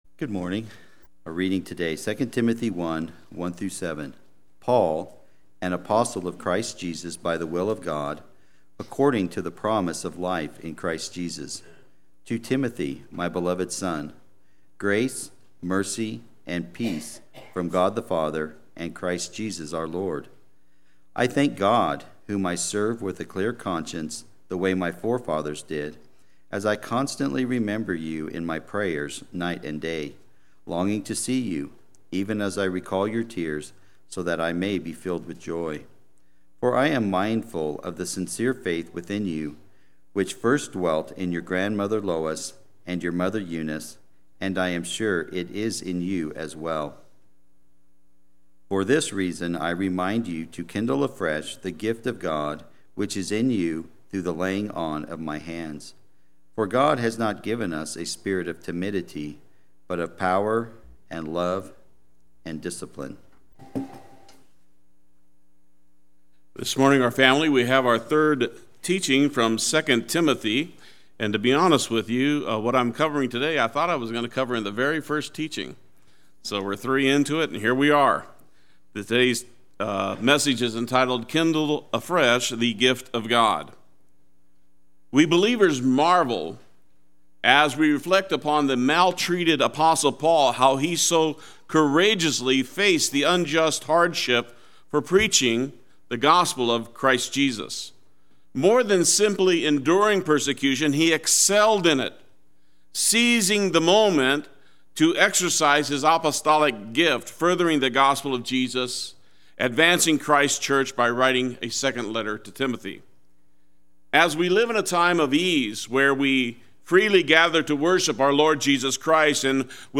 Play Sermon Get HCF Teaching Automatically.
Kindle Afresh the Gift of God Sunday Worship